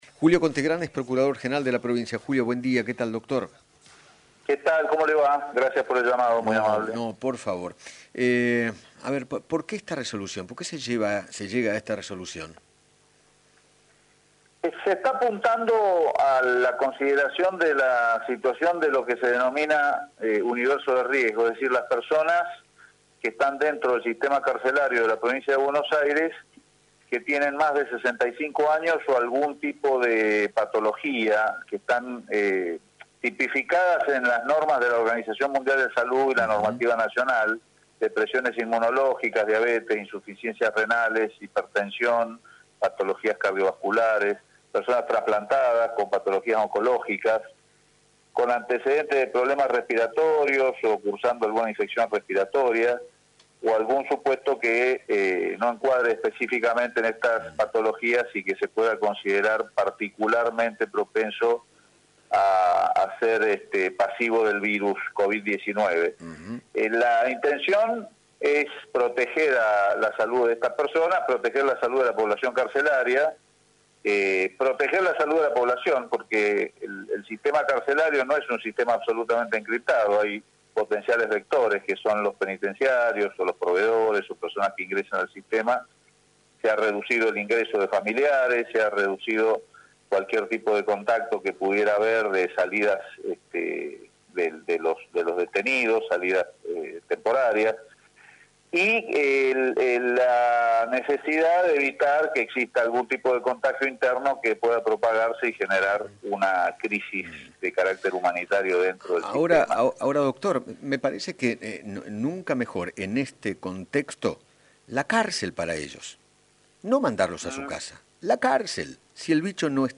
Julio Conte Grand, Procurador General de la Suprema Corte de Justicia de la Provincia de Buenos Aires, dialogó con Eduardo Feinmann sobre la resolución de la Cámara de Casación bonaerense, que otorga la excarcelación de presos que integran el grupo de riesgo ante un posible contagio de Coronavirus.